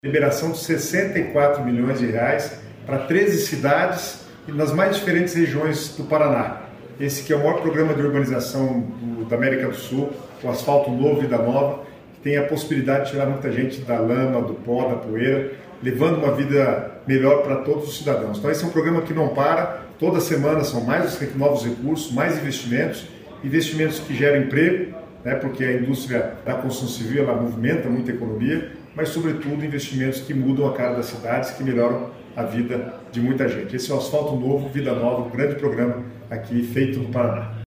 Sonora do secretário das Cidades, Guto Silva, sobre a autorização de licitações de R$ 64 milhões para pavimentação em 13 municípios